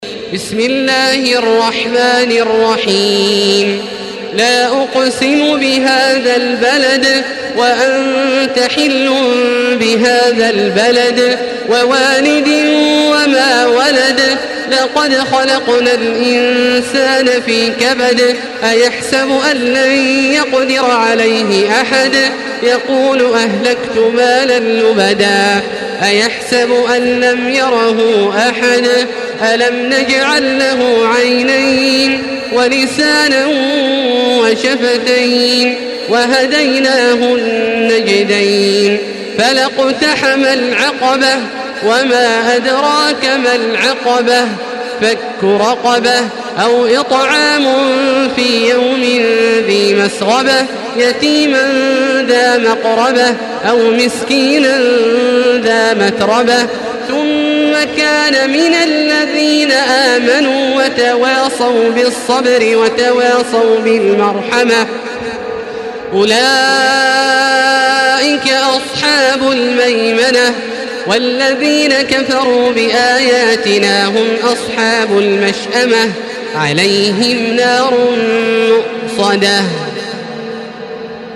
Surah আল-বালাদ MP3 by Makkah Taraweeh 1435 in Hafs An Asim narration.
Murattal